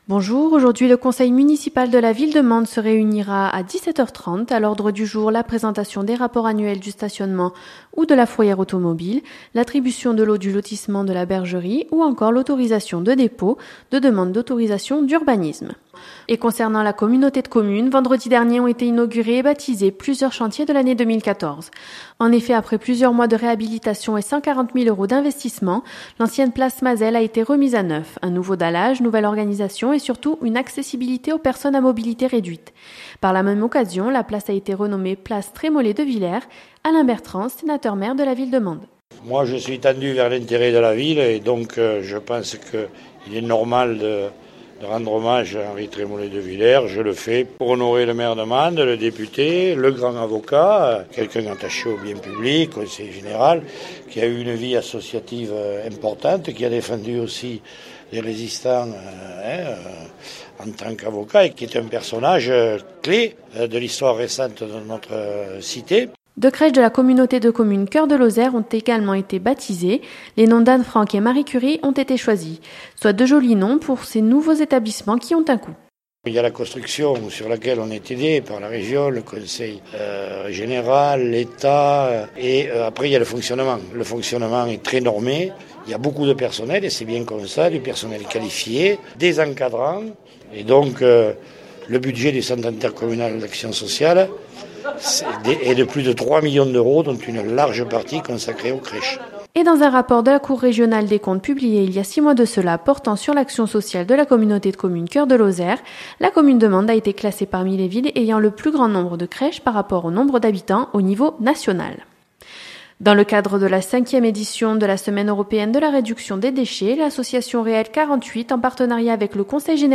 Les informations locales
• Inauguration de la place Trémolet de Villers et baptêmes des crèches Anne Frank et Marie Curie – Interview d’Alain Bertrand Sénateur – Maire de la Ville de Mende et Président de la Communauté de Commune Coeur de Lozère